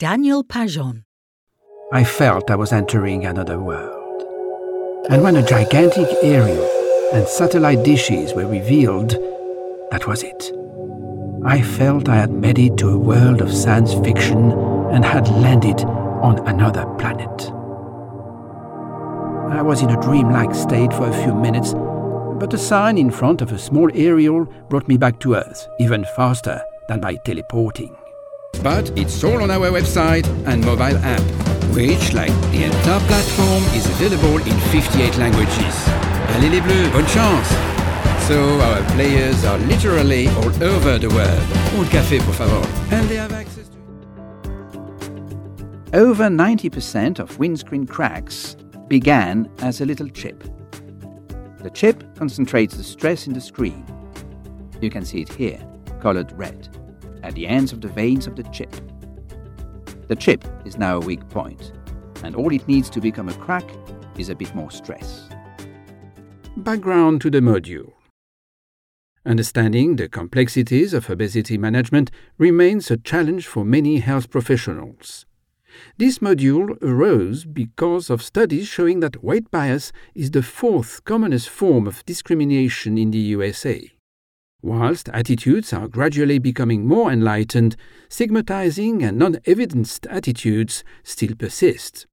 French voiceover artist: contact his agent direct for male French voice overs, recordings and session work.